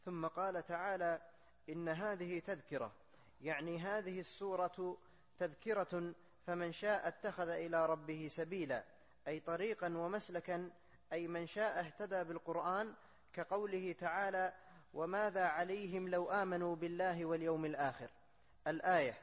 التفسير الصوتي [الإنسان / 29]